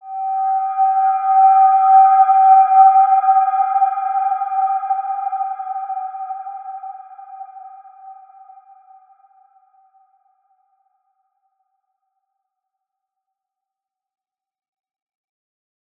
Wide-Dimension-G4-p.wav